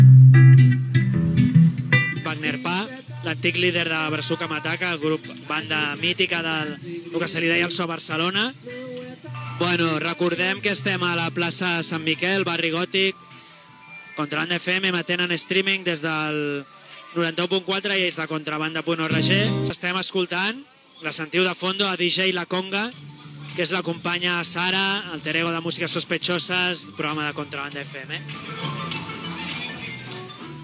Programa fet des de la plaça Sant Miquel de Barcelona com a part del Festival Llimonades organitzat pel Centre Cívic Pati Llimona.
Entreteniment